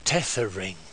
Uttal
Uttal UK Okänd accent: IPA : /ˈteðəɹɪŋ/ IPA : /ˈtɛðərɪŋ/ Ordet hittades på dessa språk: engelska Ingen översättning hittades i den valda målspråket.